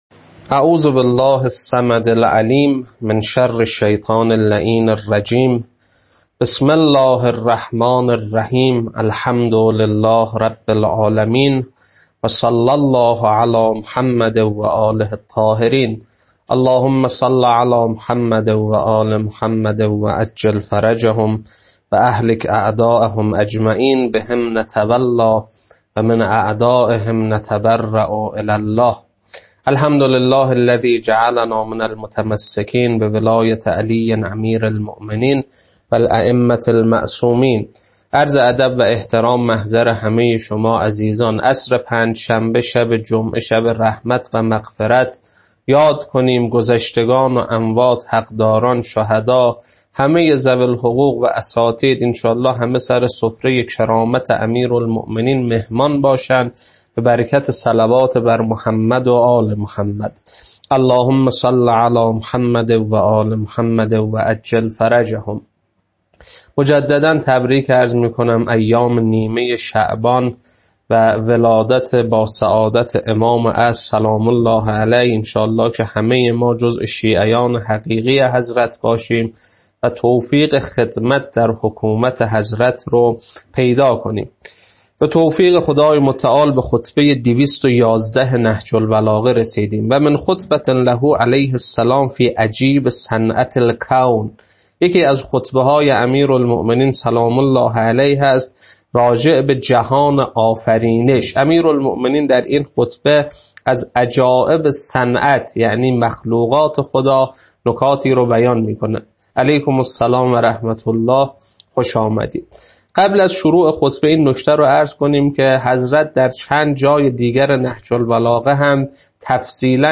خطبه 211.mp3
خطبه-211.mp3